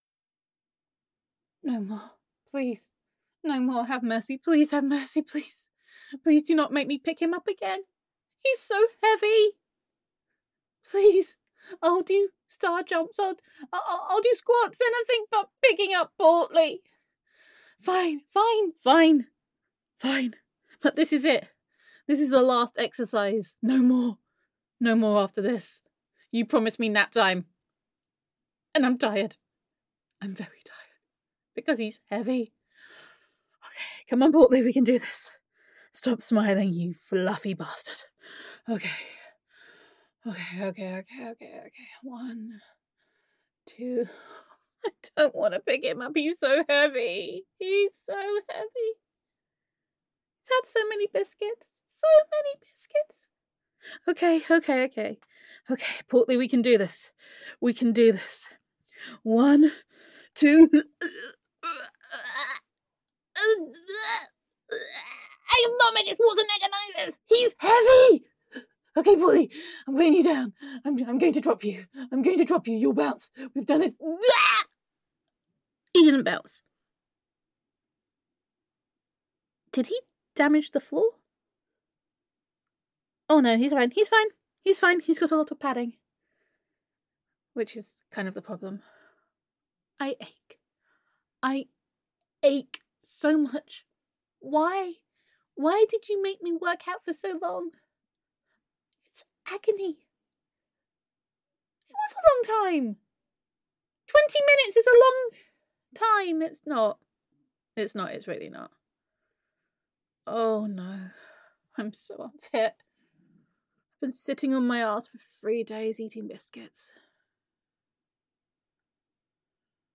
[F4A] Day Four - Working It Out [Home With Honey][Girlfriend Roleplay][Self Quarantine][Domestic Bliss][Gender Neutral][Self-Quarantine With Honey]